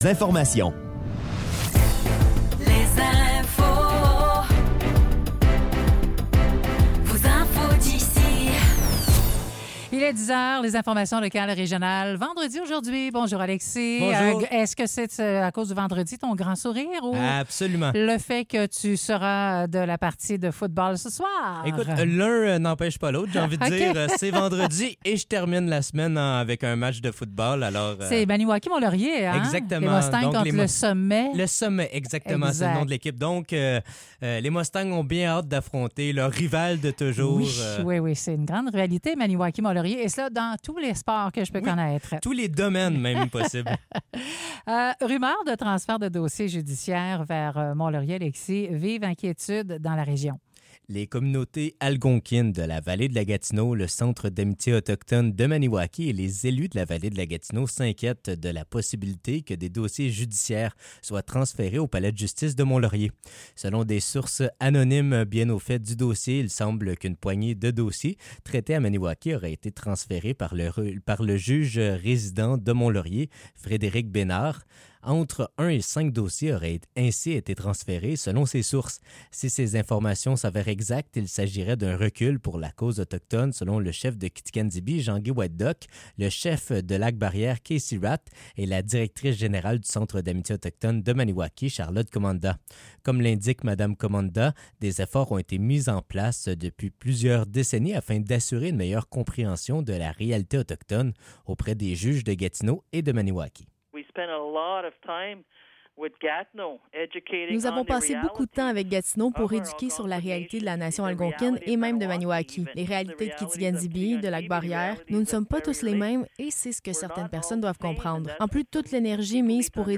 Nouvelles locales - 25 octobre 2024 - 10 h